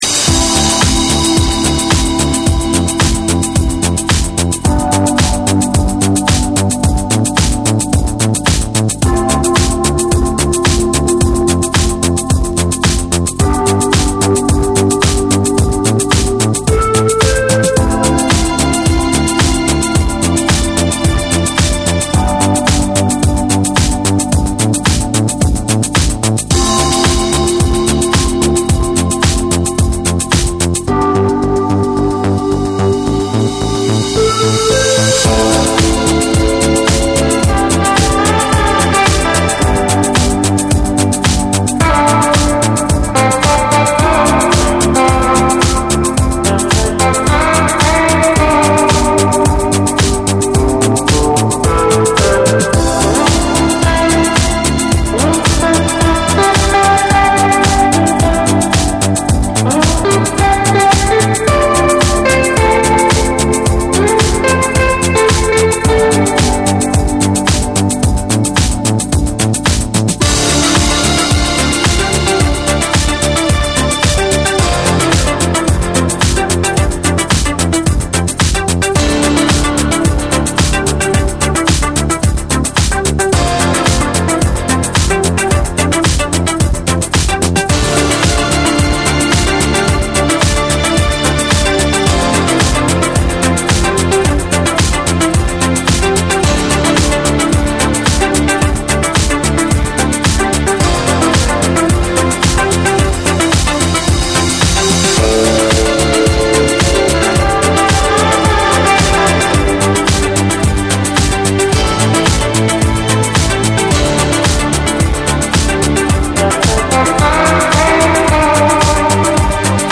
多幸感溢れる煌めくシンセ、ギターが壮大に舞いながら展開するバレアリック・ナンバーを収録。
TECHNO & HOUSE